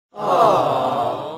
Звук восхищения толпы